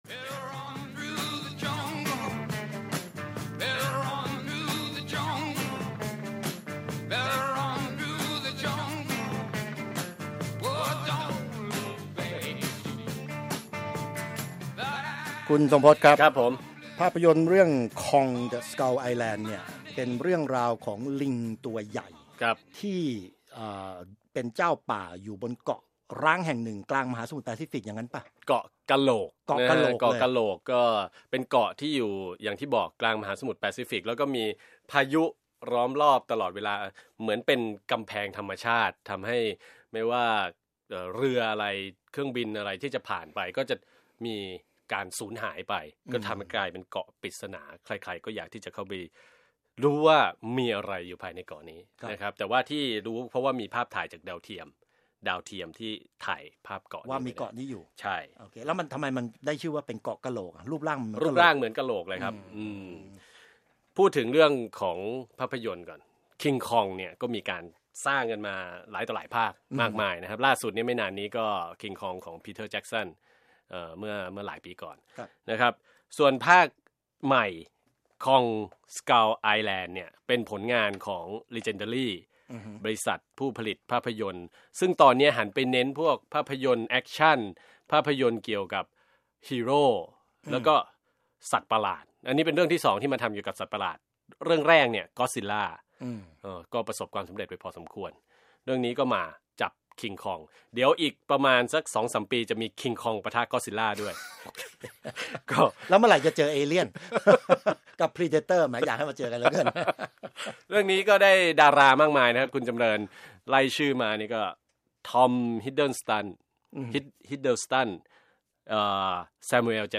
จากคลิปรายการสุดสัปดาห์กับวีโอเอ
คุยหนัง "Kong: Skull Island"